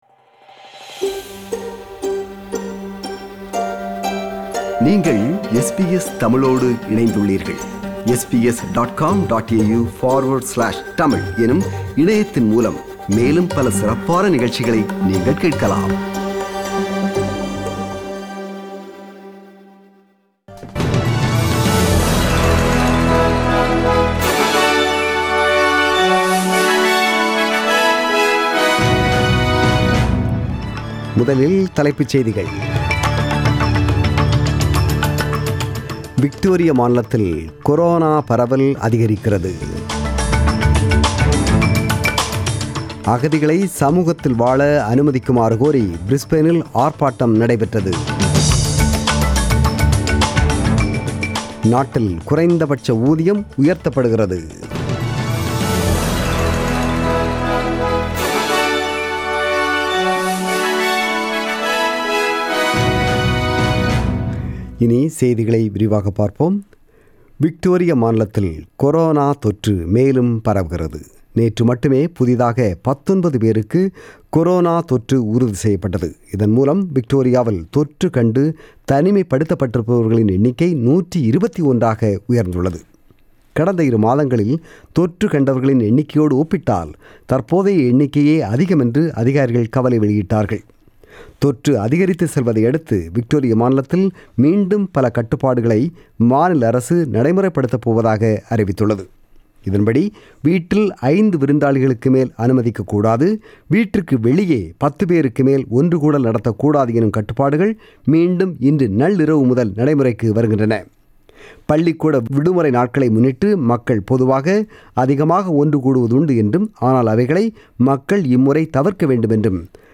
The news bulletin was broadcasted on 21 June 2020 (Sunday) at 8pm.